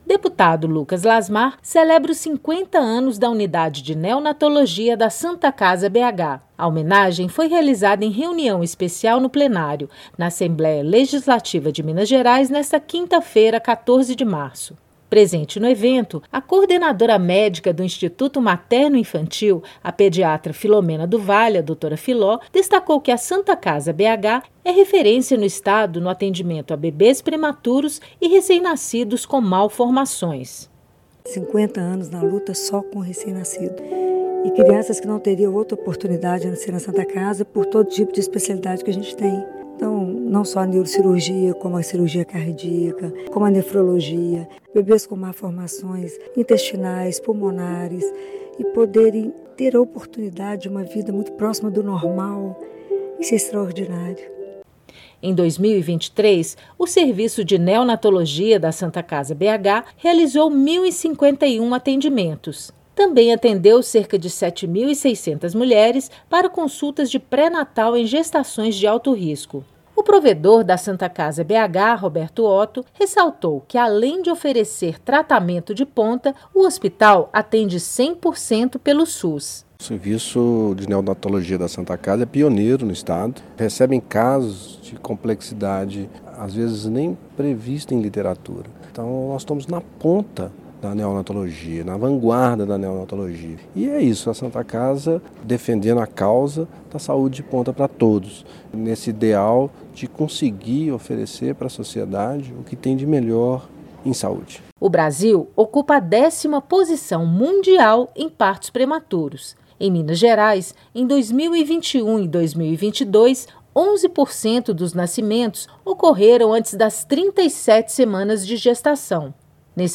Boletim de Rádio Lucas Lasmar